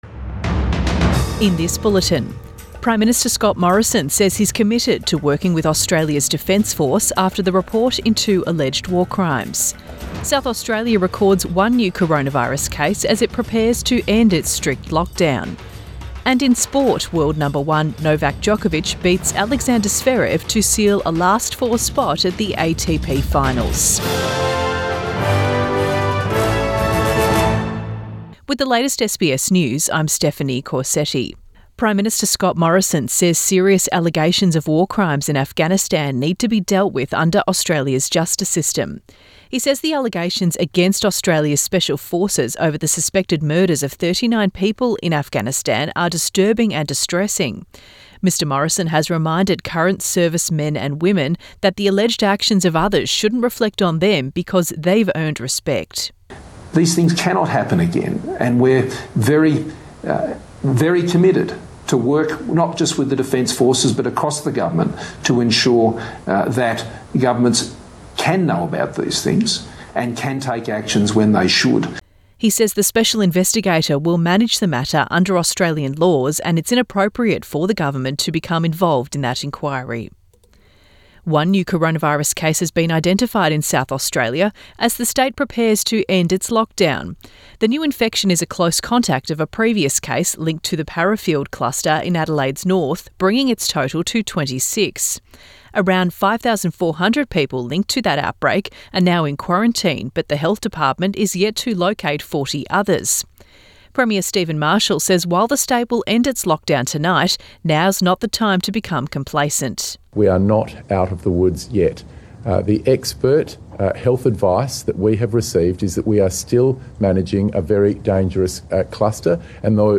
PM bulletin 21 November 2020